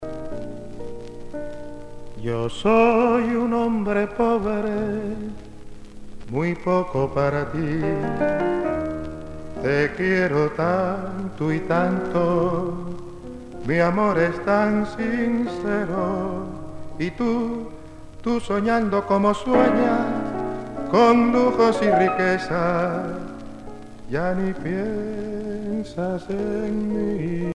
danse : boléro